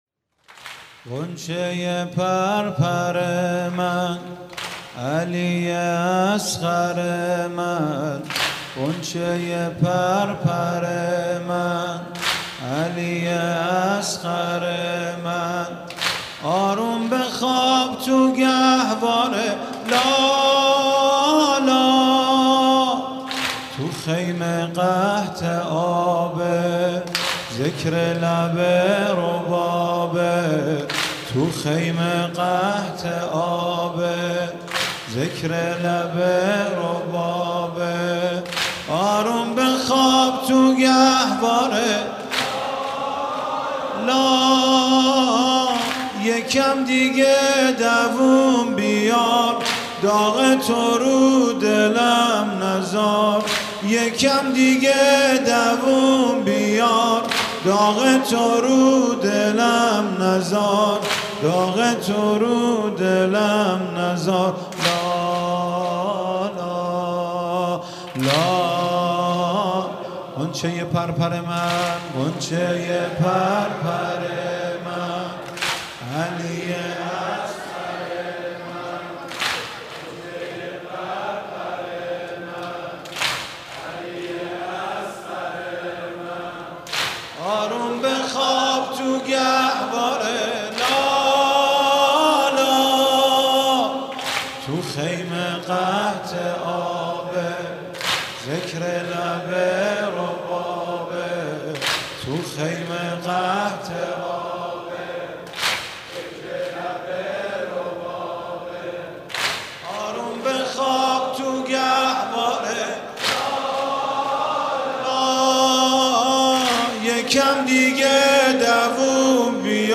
شب عاشورا محرم 95_واحد_غنچه پرپر من